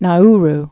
(nä-r)